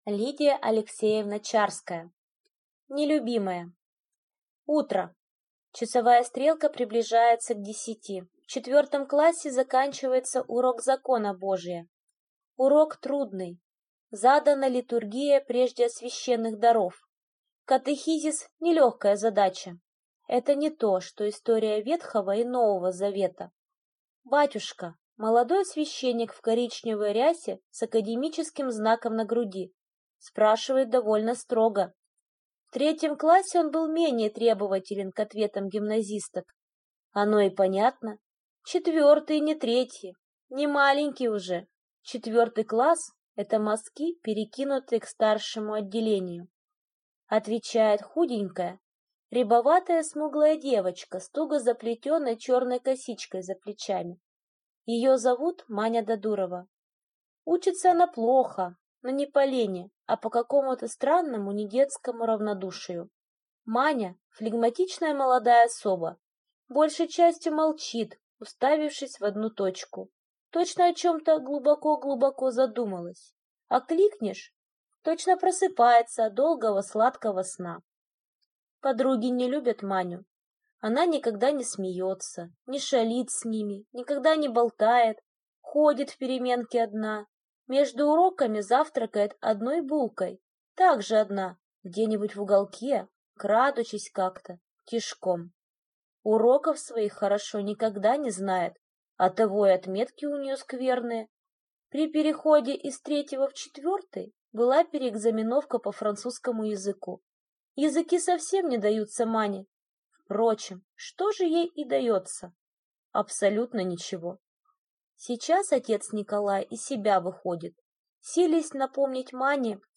Аудиокнига Нелюбимая | Библиотека аудиокниг
Aудиокнига Нелюбимая Автор Лидия Чарская